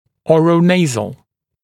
[ˌɔːrə’neɪzl][ˌо:рэ’нэйзл]носо-ротовой